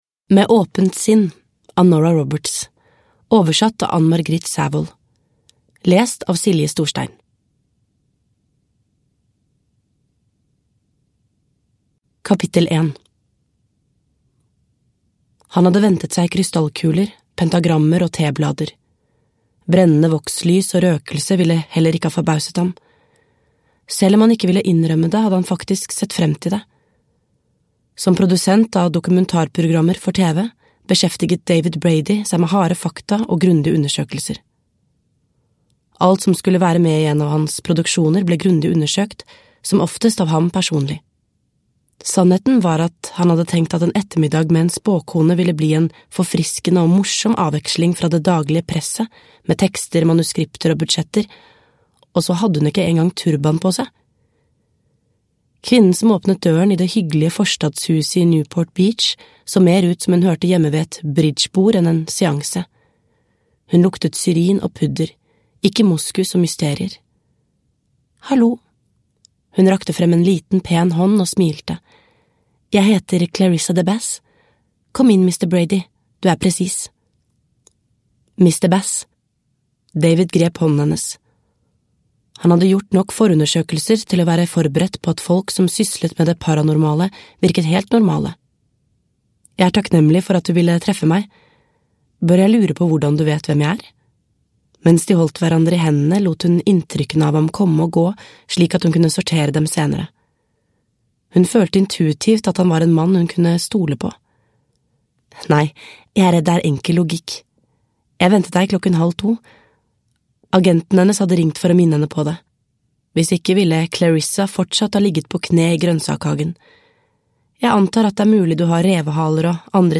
Med åpent sinn – Ljudbok – Laddas ner